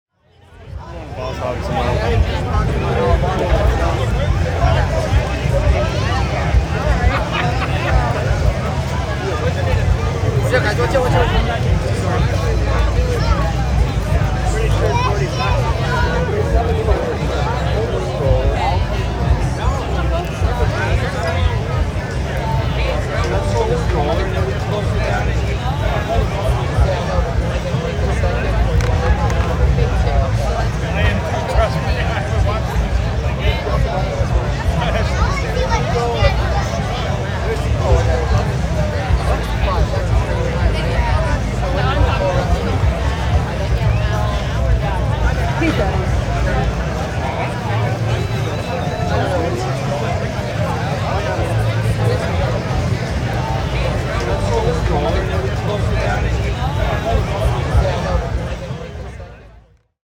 streetparty2.R.wav